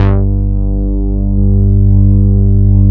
18 MOOG BASS.wav